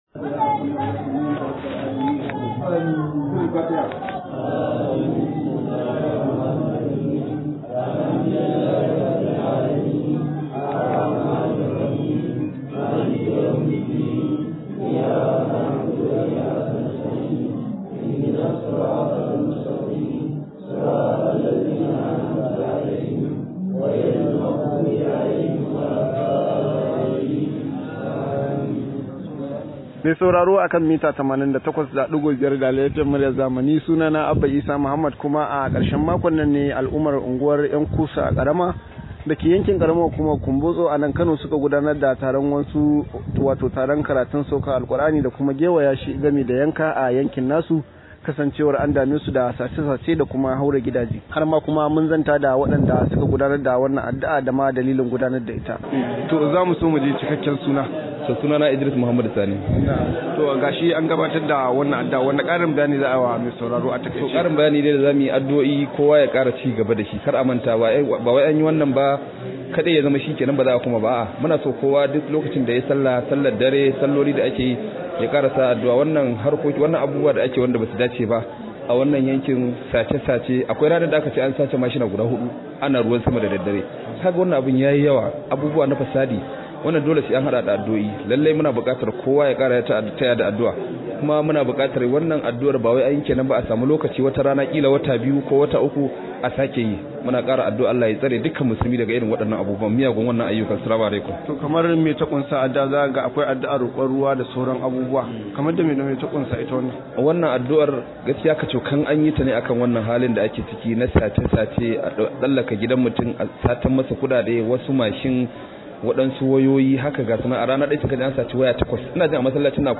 Rahoto: Mun sauke Alkur’ani domin samun saukin matsalar tsaro – Al’ummar ‘Yan Kusa